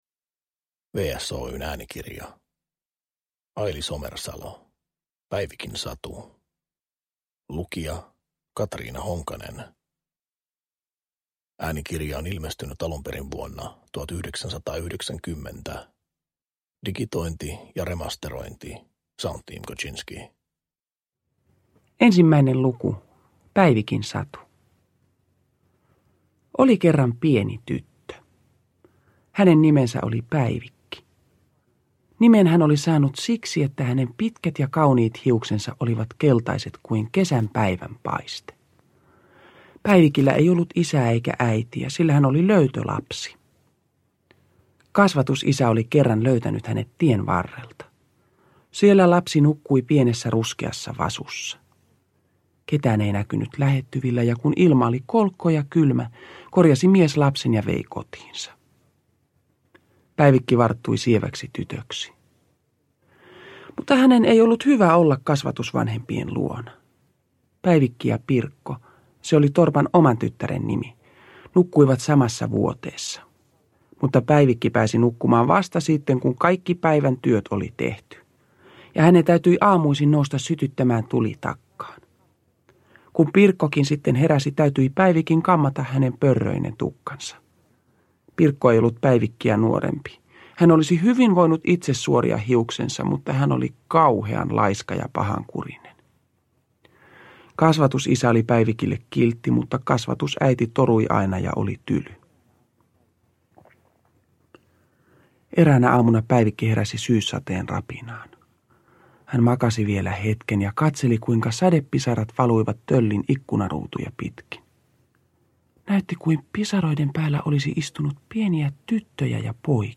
Iki-ihana Päivikin satu äänikirjana!